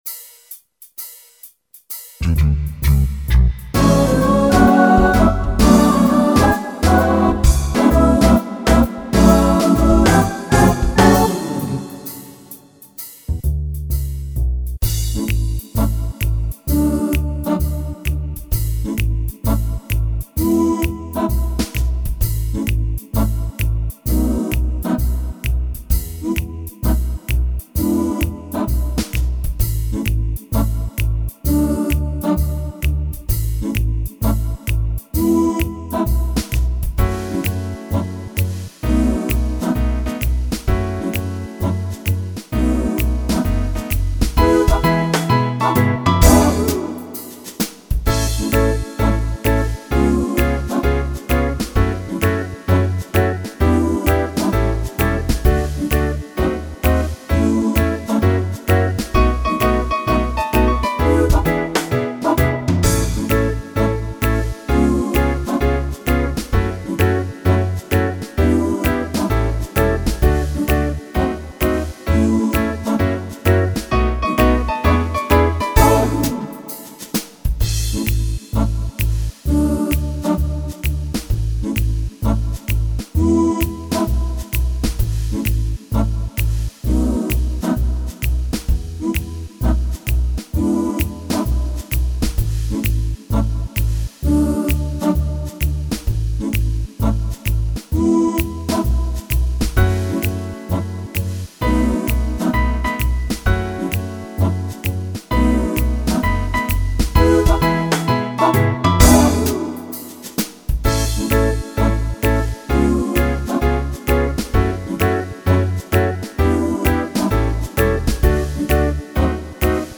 Слушать минус
караоке
минусовка